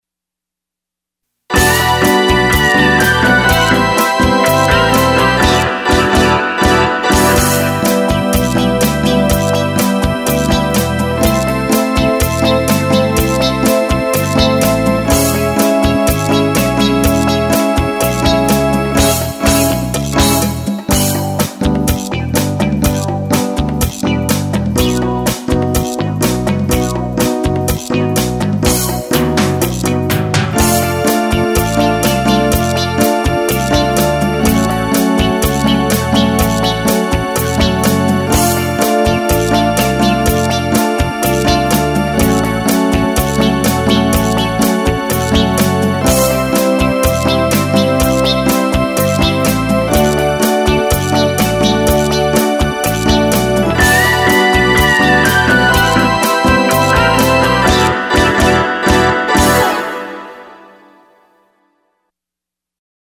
that Latin rock thing!